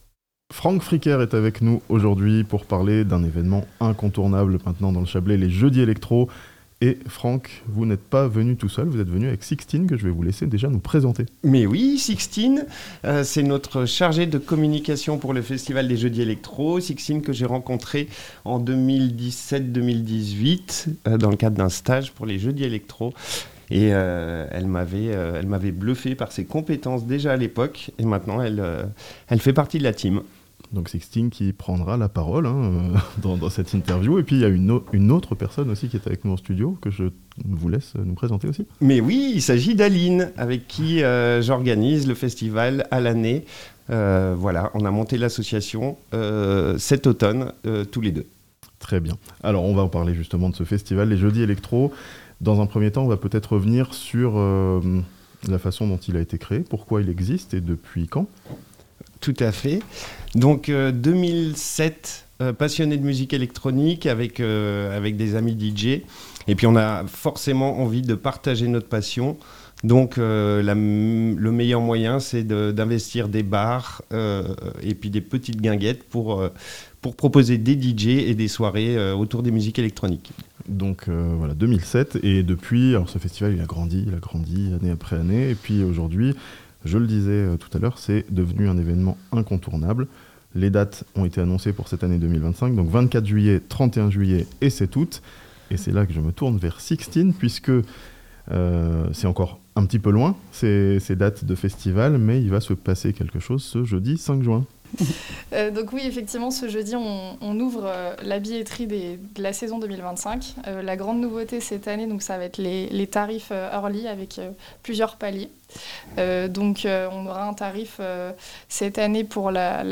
La billetterie des Jeudis Electro ouvre ce jeudi 5 juin (interview)